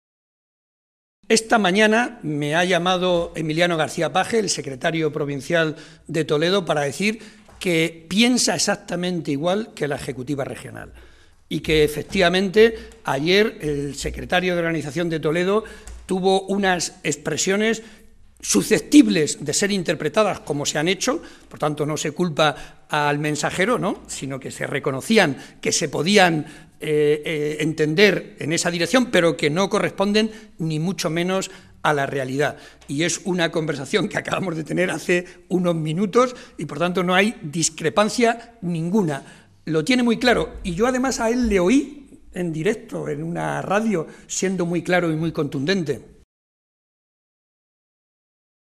José María Barreda, secretario General del PSOE de Castilla-La Mancha
Cortes de audio de la rueda de prensa